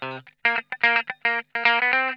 TWANGY 1.wav